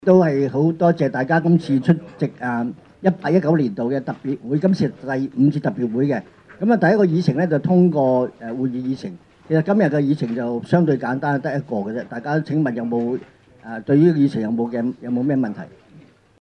委员会会议的录音记录